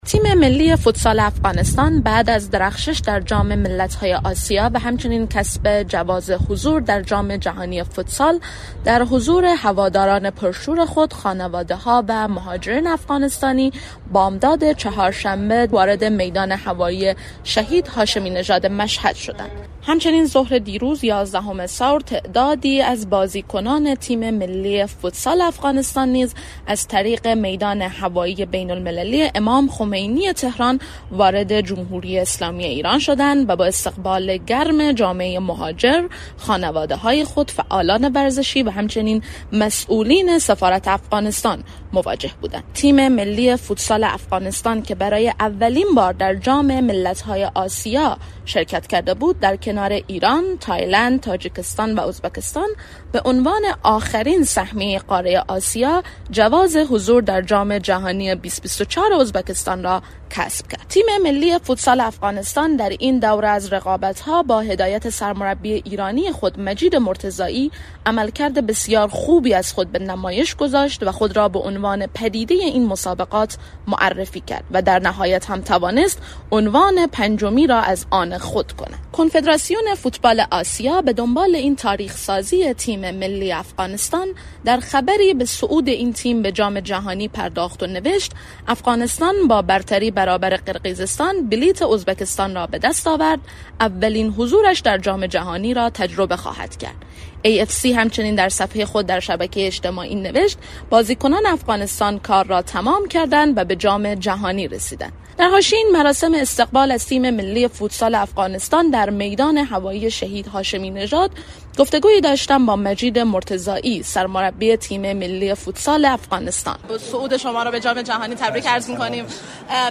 استقبال پرشور از تیم ملی فوتسال افغانستان در فرودگاه مشهد